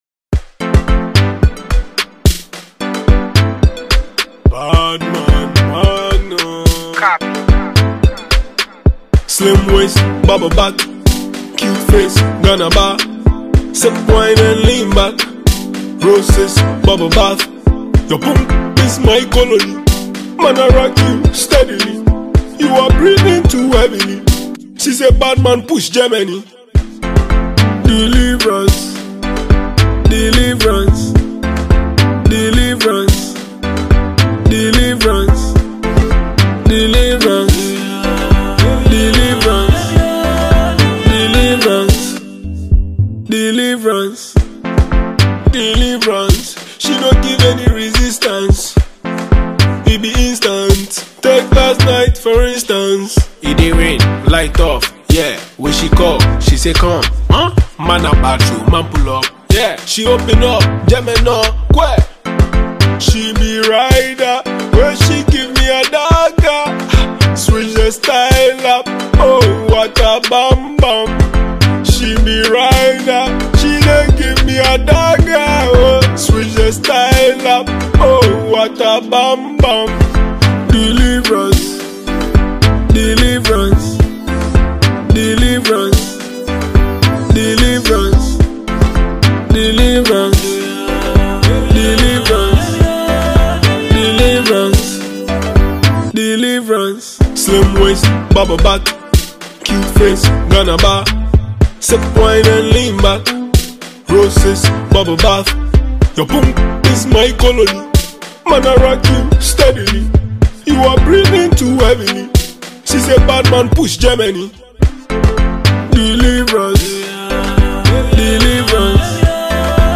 groovy record